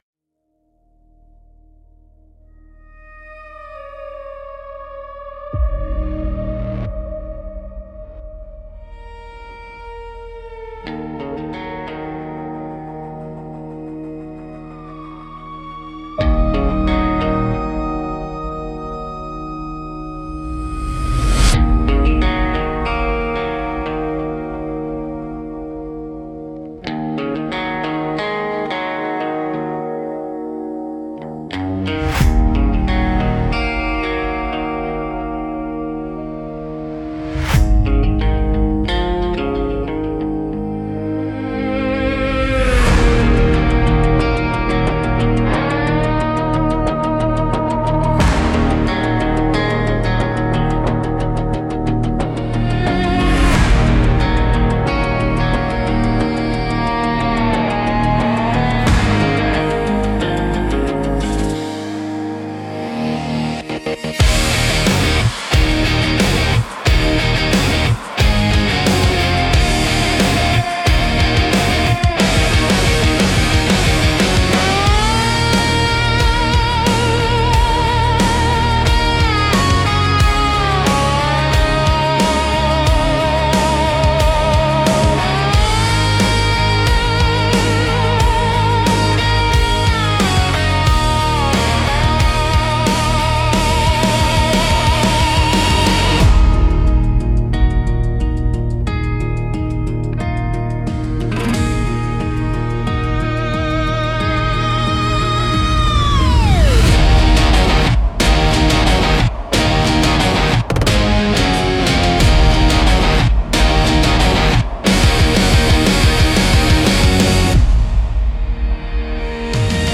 Hybrid Western Power